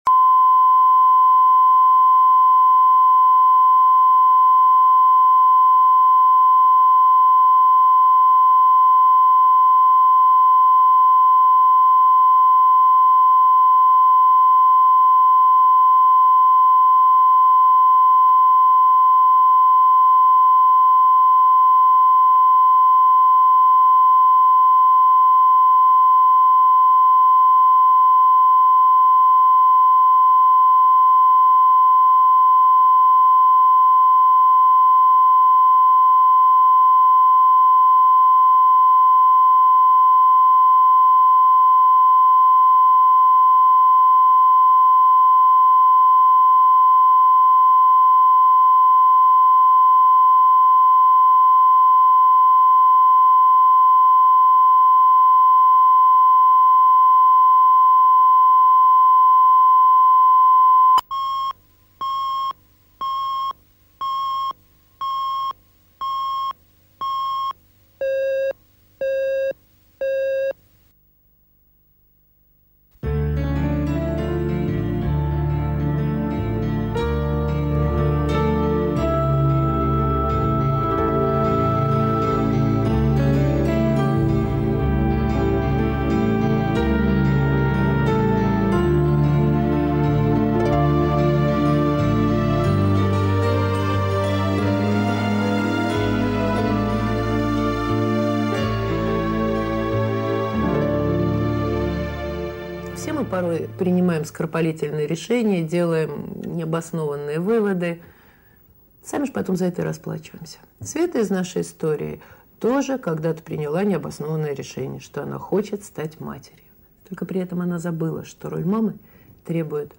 Аудиокнига Долгожданный сын | Библиотека аудиокниг
Прослушать и бесплатно скачать фрагмент аудиокниги